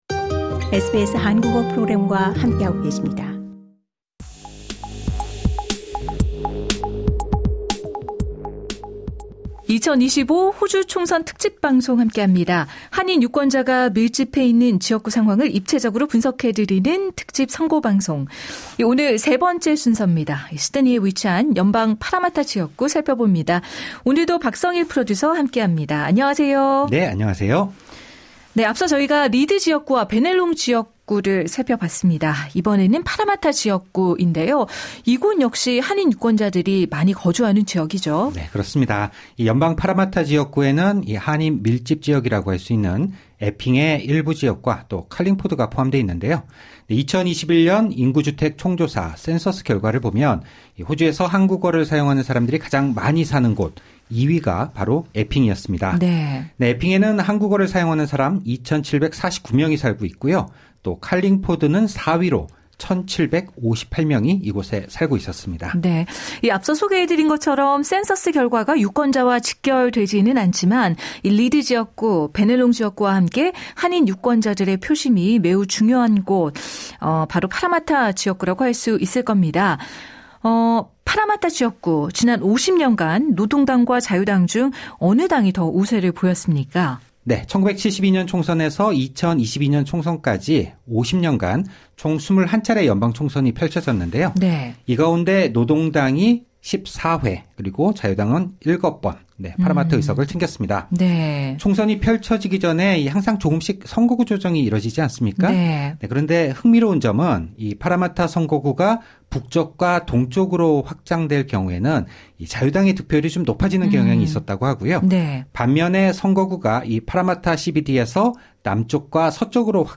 에핑· 칼링포드 포함 SBS Korean 11:59 Korean SBS 한국어 프로그램에서는 한인 유권자가 밀집해 있는 지역구의 상황을 입체적으로 분석해 드리는 특집 방송을 준비했습니다. 세 번째 순서로 시드니에 위치한 연방 파라마타(Parramatta) 지역구를 살펴봅니다.